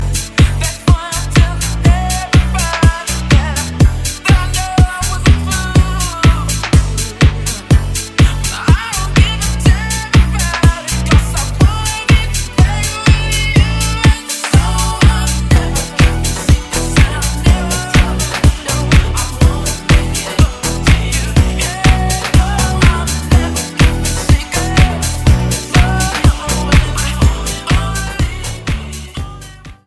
REMIX TRACKS